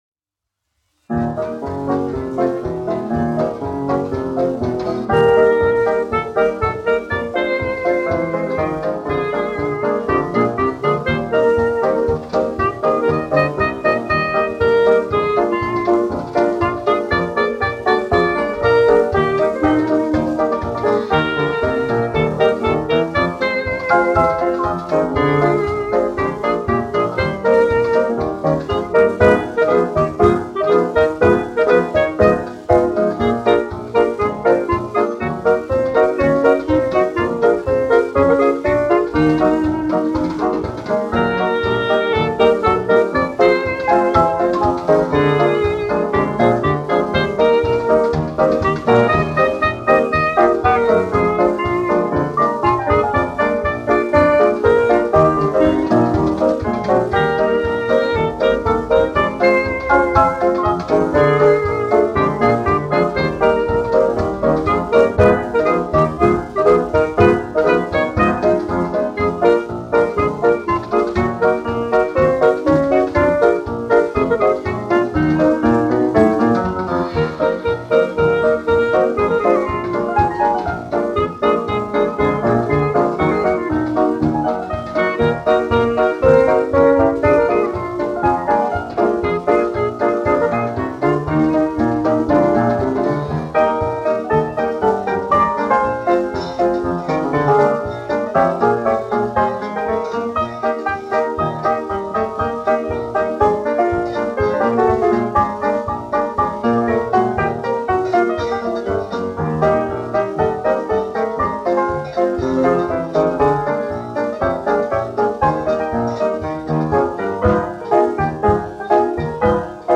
1 skpl. : analogs, 78 apgr/min, mono ; 25 cm
Fokstroti
Populārā instrumentālā mūzika
Skaņuplate